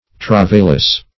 Search Result for " travailous" : The Collaborative International Dictionary of English v.0.48: Travailous \Trav"ail*ous\, a. Causing travail; laborious.